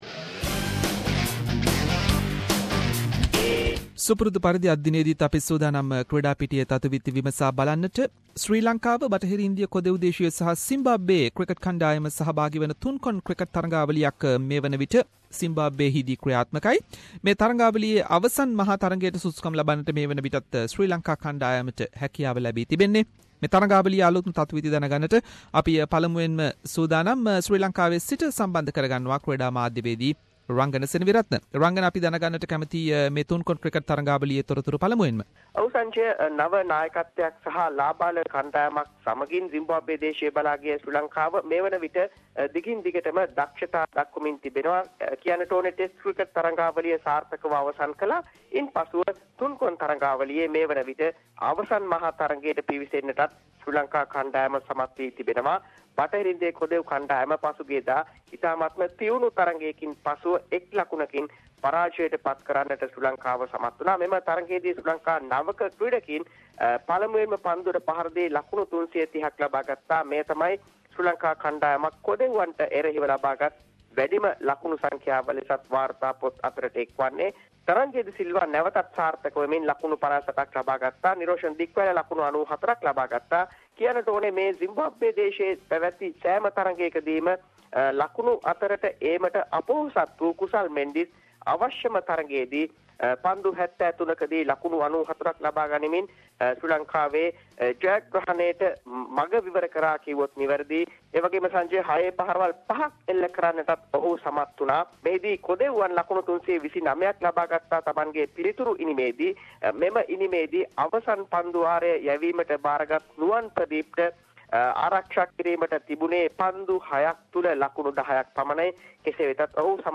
In this weeks SBS Sinhalese sports wrap…. Latest from Sri Lanka cricket tour to Zimbabwe, Mahela Jayawardene appointed Mumbai Indians head coach and many more local and international sports news.